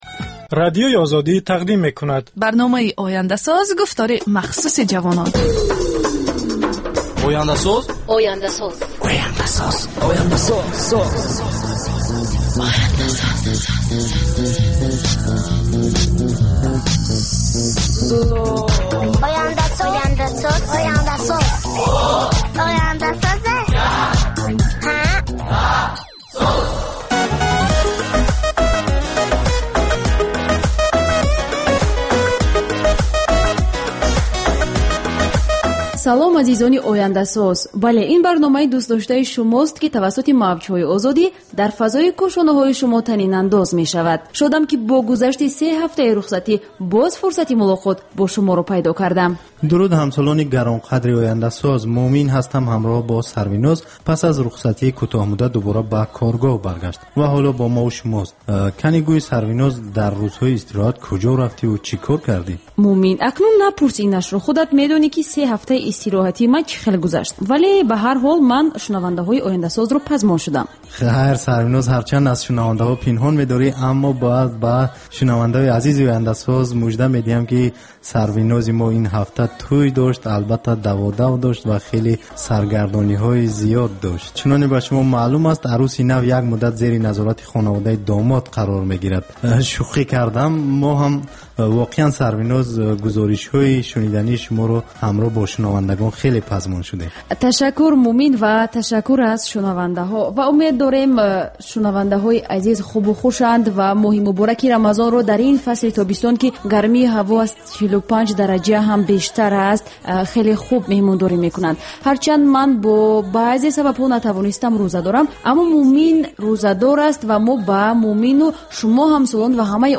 "Ояндасоз" барномаи вижаи ҷавонон, ки муҳимтарин масоили сиёсӣ, иқтисодӣ, иҷтимоӣ ва фарҳангии Тоҷикистону ҷаҳонро аз дидгоҳи худи онҳо ва коршиносон таҳлил ва баррасӣ мекунад. Бар илова, дар ин гуфтор таронаҳои ҷаззоб ва мусоҳибаҳои ҳунармандон тақдим мешавад.